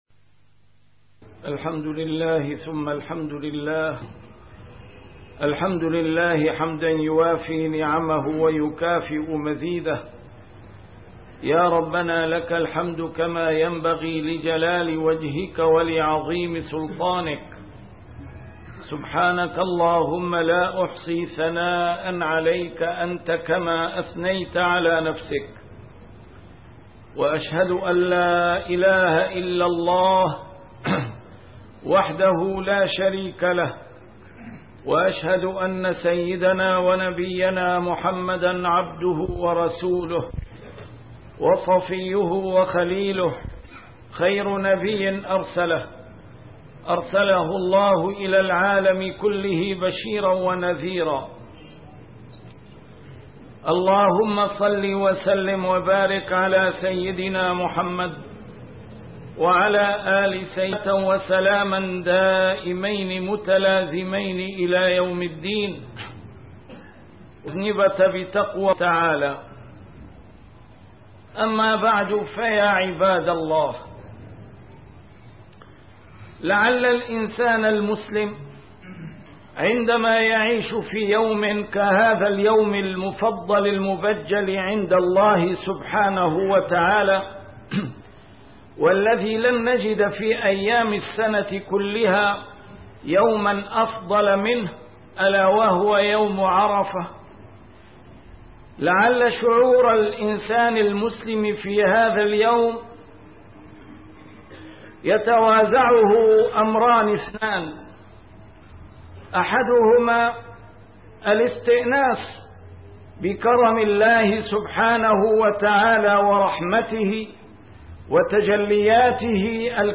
A MARTYR SCHOLAR: IMAM MUHAMMAD SAEED RAMADAN AL-BOUTI - الخطب - بتحقق هذا الشرط يثمر يوم عرفة أثره المنشود